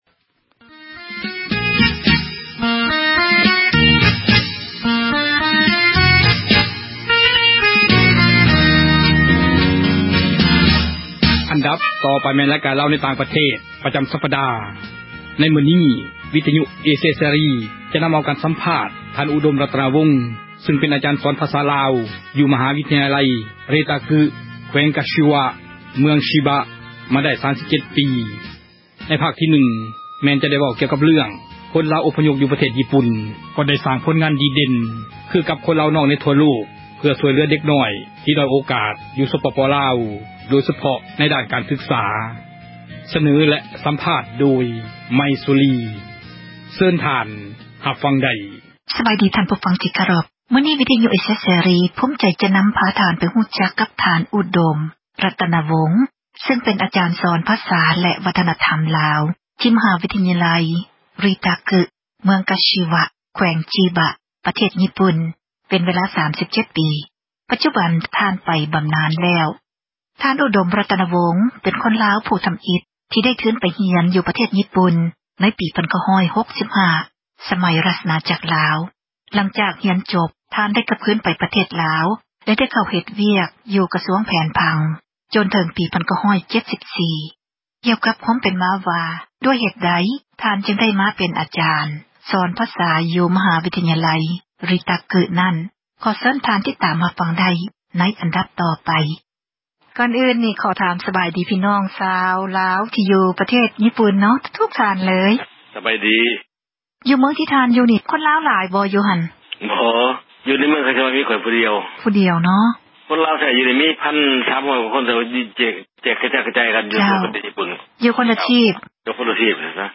ການ ສຳພາດ